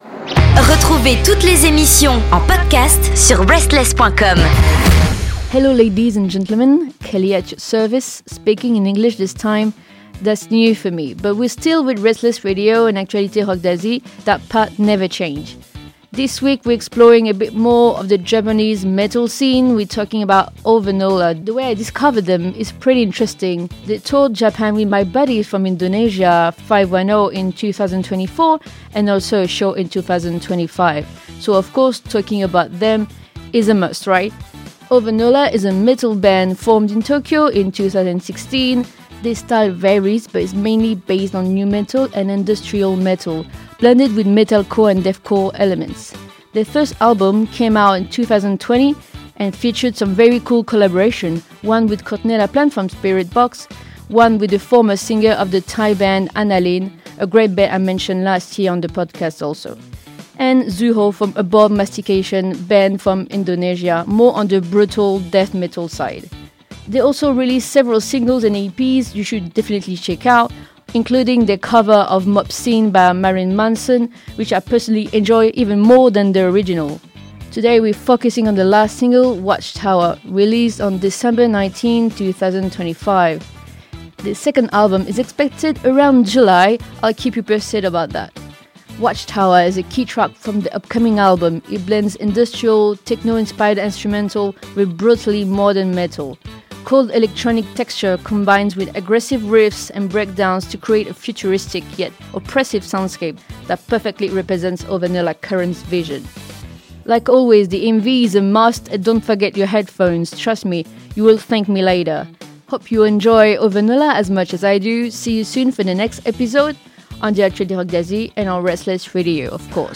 Head to Japan with OVENOLA, a metalcore band combining raw power, clean vocals and screams, and an intensity built for the stage.